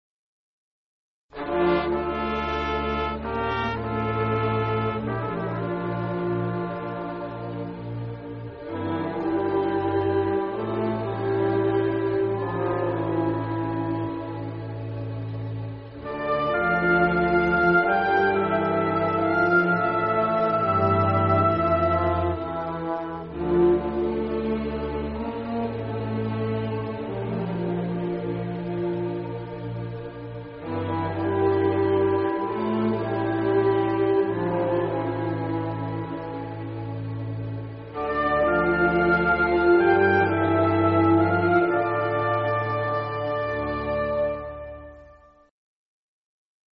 مرثیه بی‌کلام